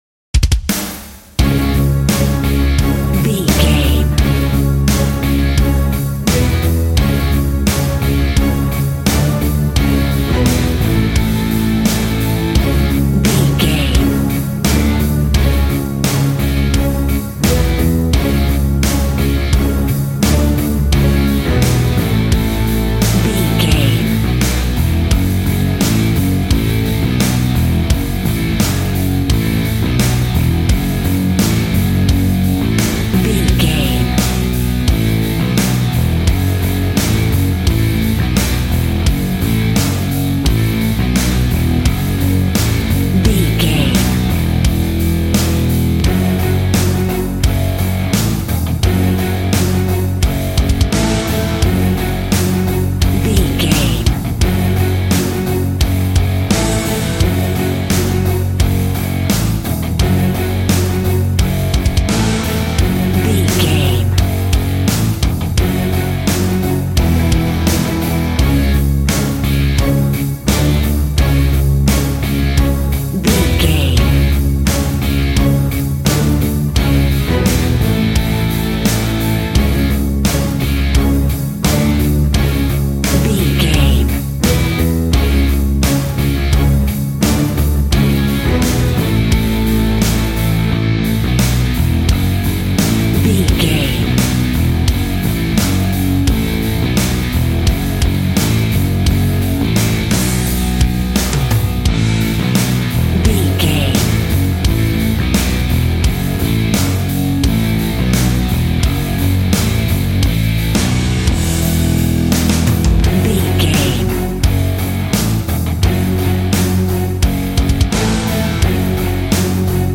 Ionian/Major
angry
aggressive
electric guitar
drums
bass guitar